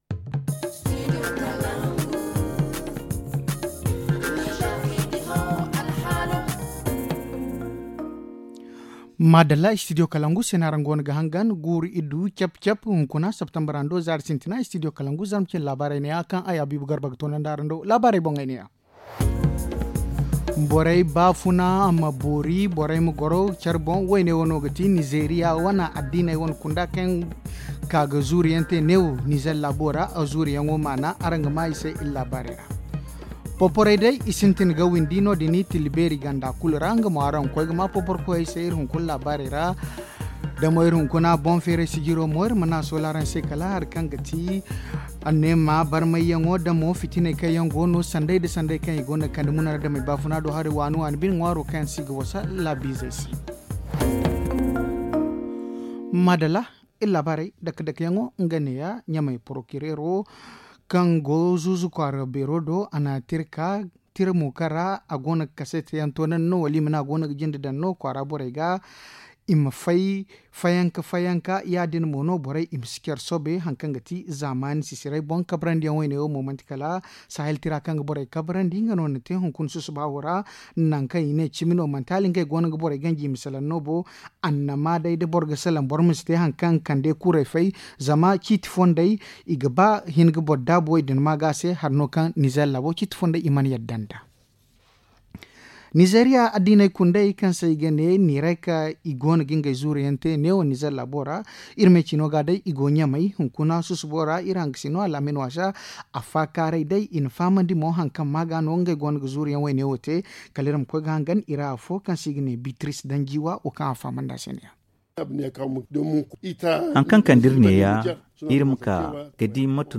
Le journal du 01 septembre 2021 - Studio Kalangou - Au rythme du Niger